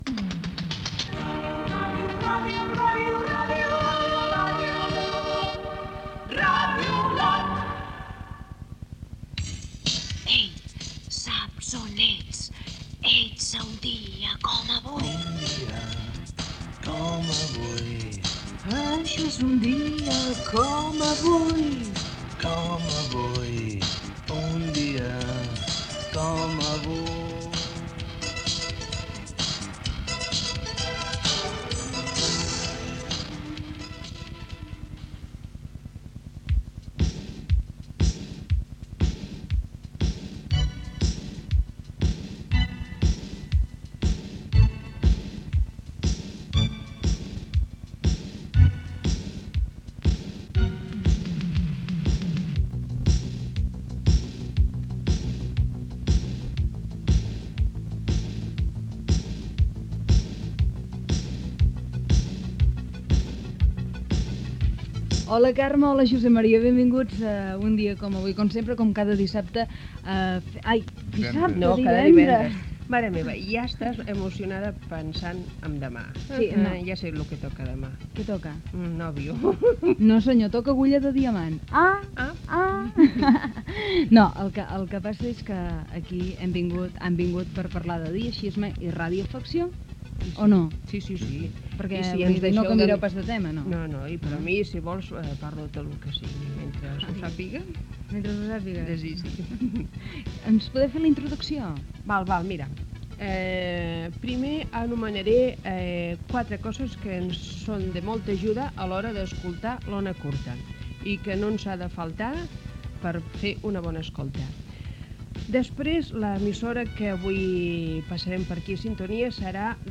Indicatiu de l'emissora, careta del programa
FM